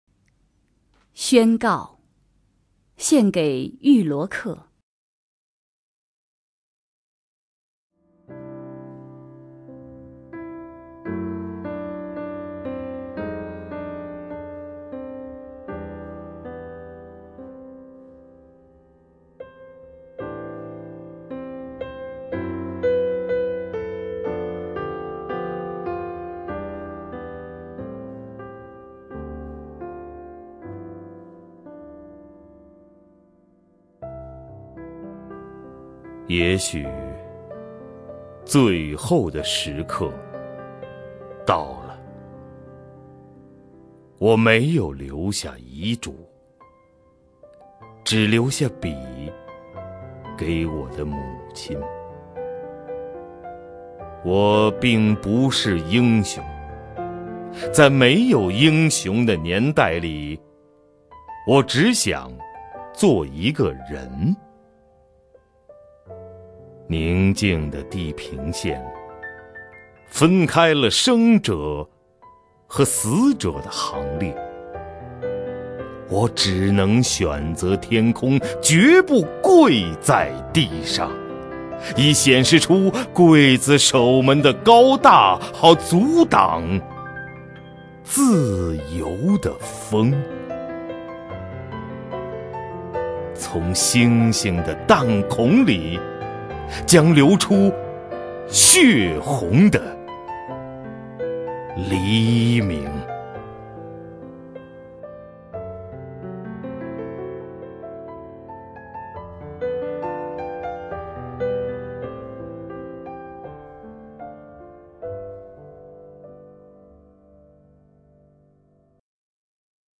首页 视听 名家朗诵欣赏 赵屹鸥
赵屹鸥朗诵：《宣告——给遇罗克烈士》(北岛)
XuanGao-GeiYuLuoKeLieShi_BeiDao(ZhaoYiOu).mp3